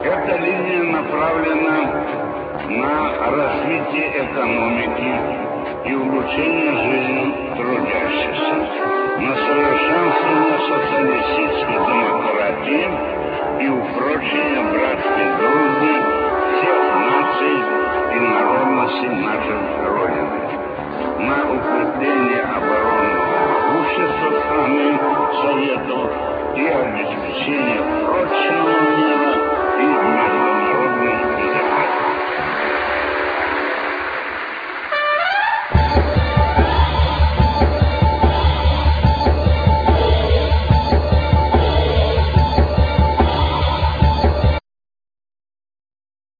Trumpet,Rhodes
Guiatr,Noises
Bass
Beats
Spoken words
Samples,Programming